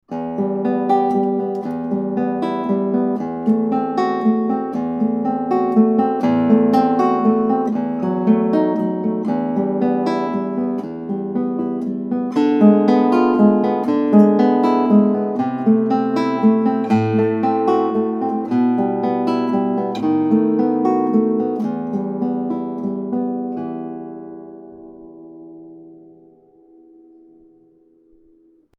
Prelude in E Minor by Matteo Carcassi (1792-1853) is a triple meter arpeggio piece based on the P-i-m-a-i-m pattern.
Finally, Prelude is at its best in a moderato tempo.
In my video, a ritardando is used in the final measure of Prelude in E Minor.